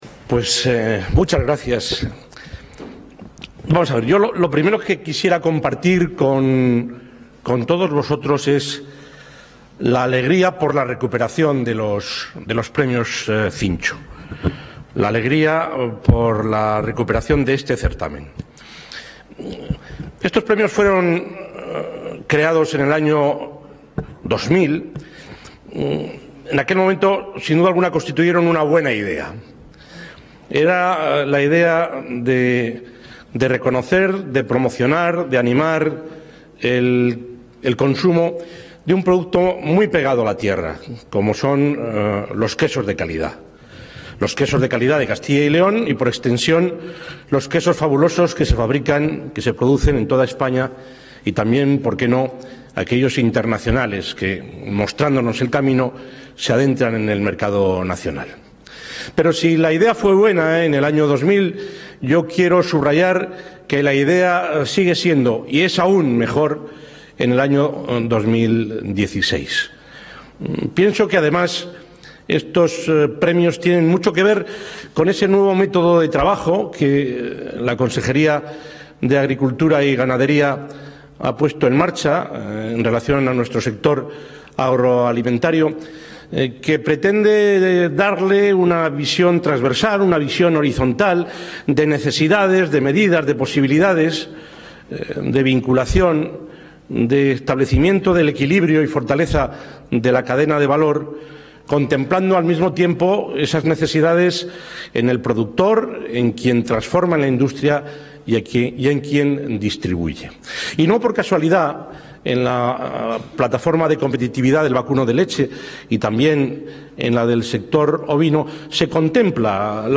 El presidente de la Junta de Castilla y León, Juan Vicente Herrera, ha presidido hoy en Palencia la entrega de los Premios Cincho 2016, un...
El presidente Herrera clausura los Premios Cincho 2016.